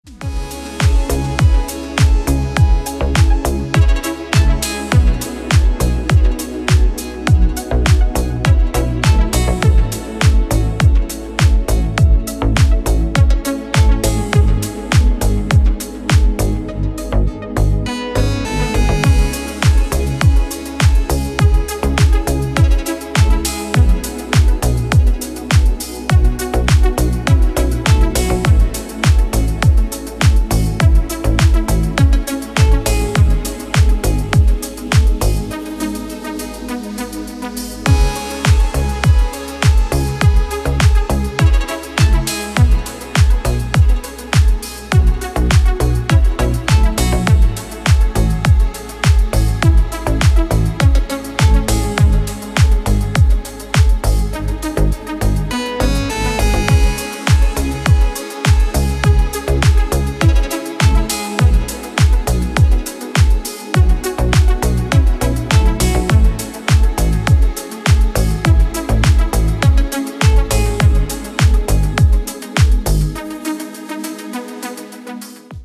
Deep House рингтоны
Дип Хаус отбивка на вызов